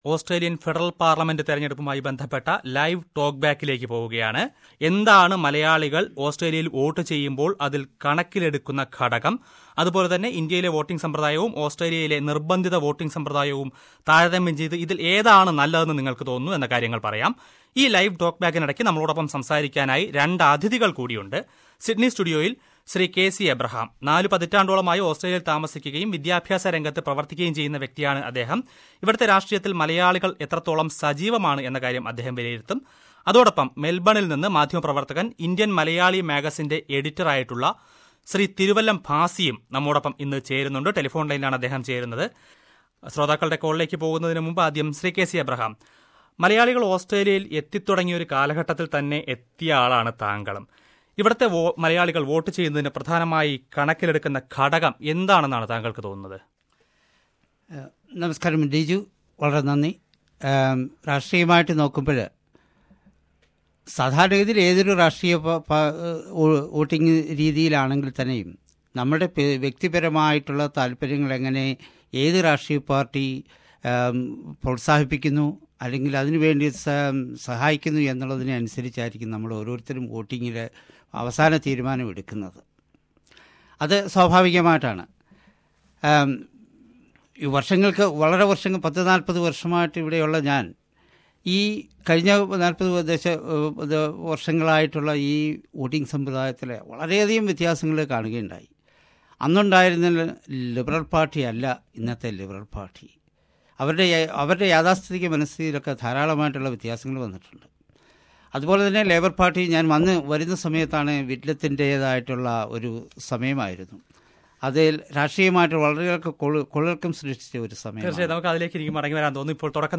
SBS Malayalam Radio conducted a live talk-back on September 1st, ahead of the Federal Election. People talked about the issues the Malayalee community consider while voting and compared the compulsory voting system in Australia with the system in India.